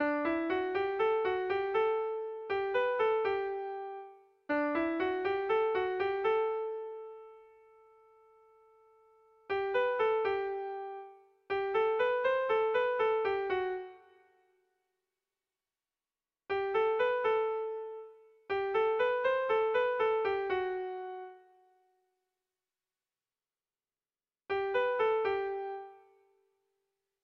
Zortziko berezia, 4 puntuz (hg) / Lau puntuko berezia (ip)
AABB